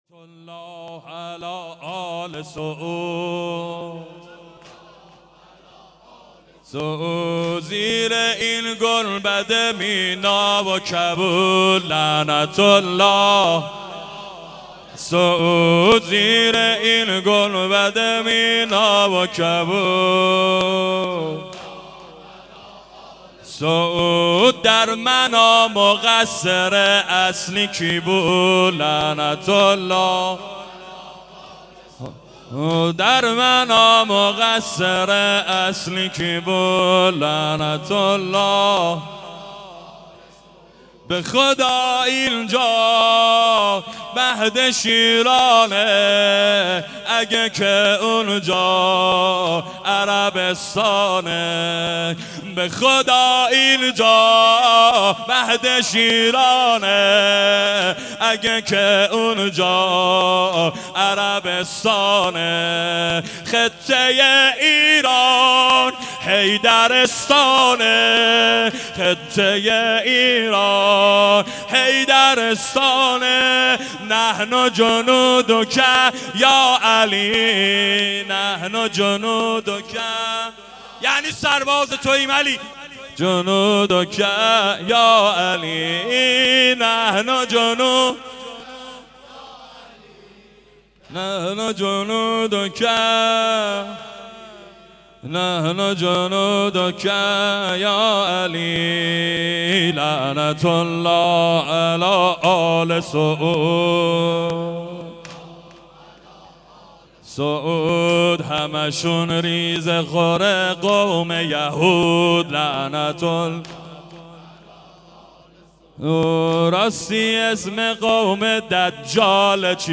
به مناسبت شهدای منا - واحد